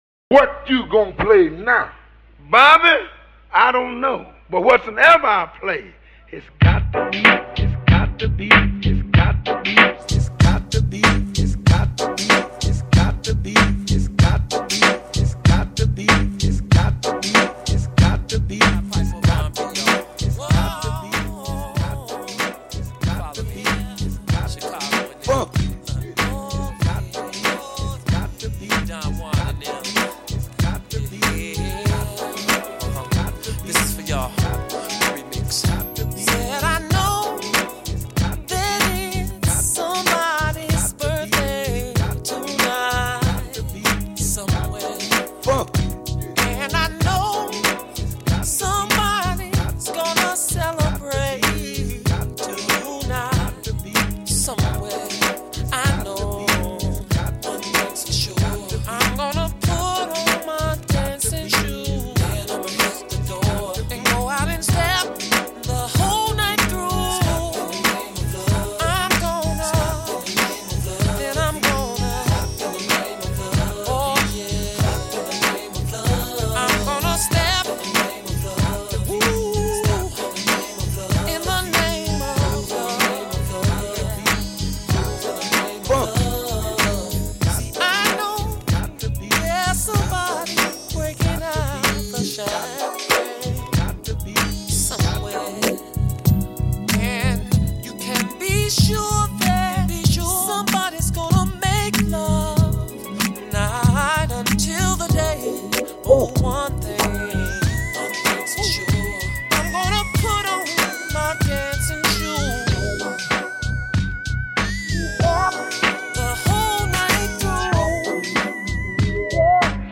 Ballroom
Description: Something to step to